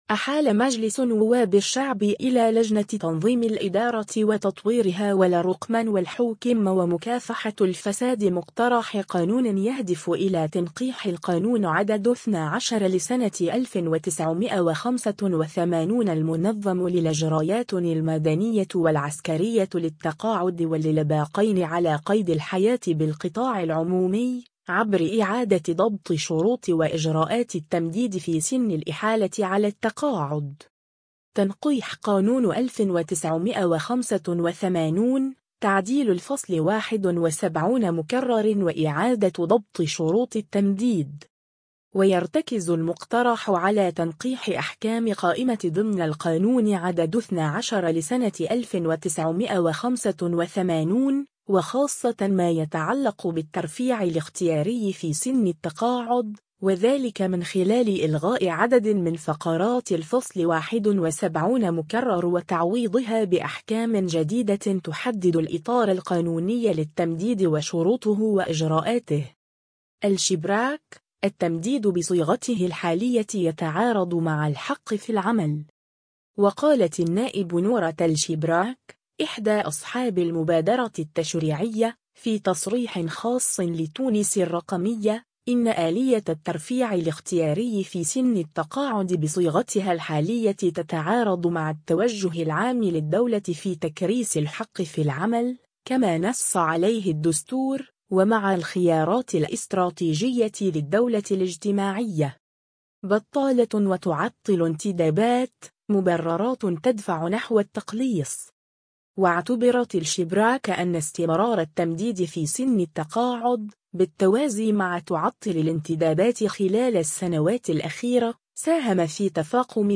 يهمّ طالبي الشغل: نحو تقليص التمديد في التقاعد الاختياري إلى سنة واحدة..النائب نورة الشبراك تُوضح (فيديو)
وقالت النائب نورة الشبراك، إحدى أصحاب المبادرة التشريعية، في تصريح خاص لـ”تونس الرقمية”، إن آلية الترفيع الاختياري في سن التقاعد بصيغتها الحالية “تتعارض مع التوجه العام للدولة في تكريس الحق في العمل”، كما نصّ عليه الدستور، ومع الخيارات الاستراتيجية للدولة الاجتماعية.